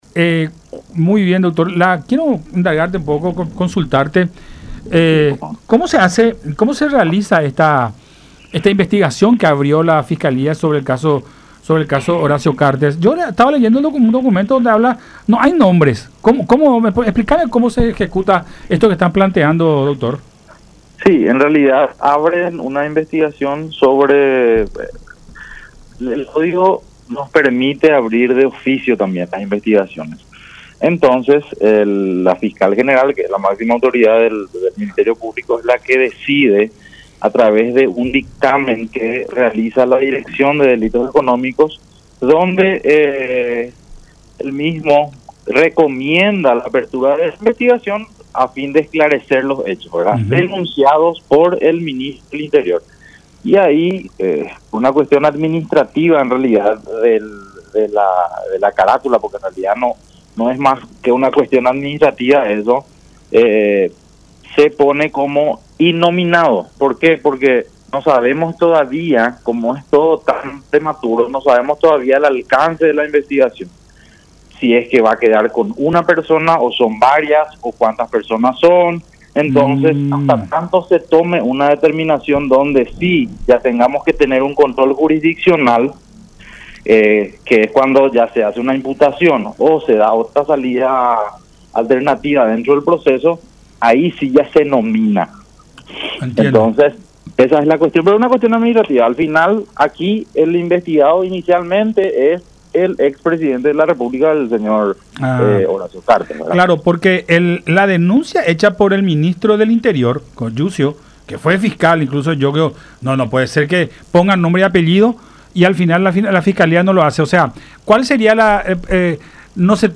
Uno de los fiscales del caso, Osmar Legal, explicó por qué no se lo cita al expresidente de la República en el anuncio de la institución.
“El Código nos permite abrir de oficio también las investigaciones. Entonces, la fiscal general del Estado, Sandra Quiñónez, es la que recomienda, a través de un dictamen que realiza la Dirección de Delitos Económicos, la apertura de esta investigación a fin de esclarecer los hechos denunciados por el ministro del Interior”, expuso Legal en diálogo con Buenas Tardes La Unión.